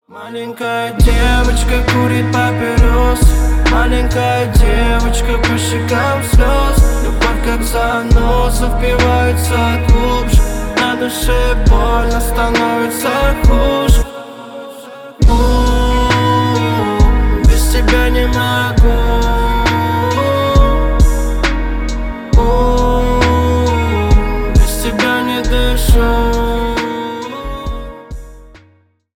• Качество: 320 kbps, Stereo
Поп Музыка
грустные